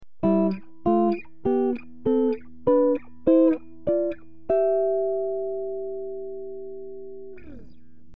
HARMONIZING 3RD AND 5TH INTERVALS
The 3rd 5th harmony in my opinion is the coolest sounding of all due to the fact there is no rootmaking it sound like you're playing a different key.
007_3_5_harmony.mp3